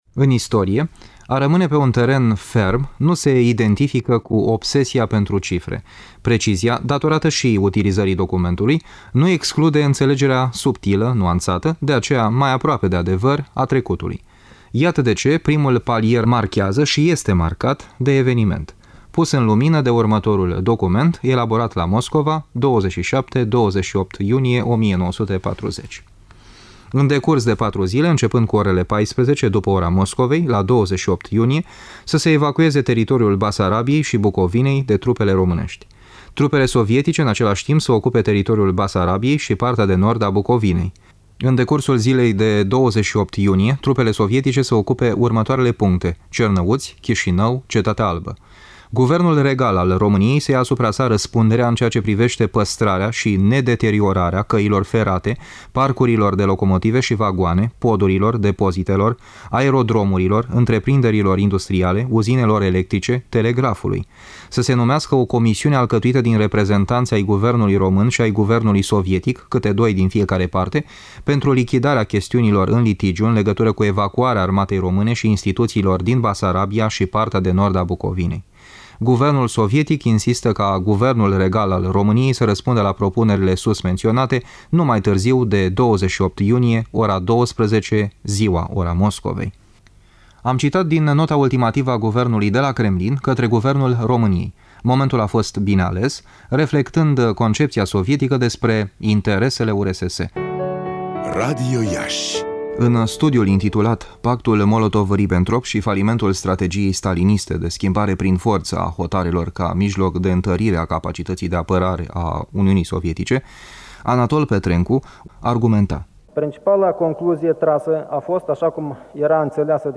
documentar audio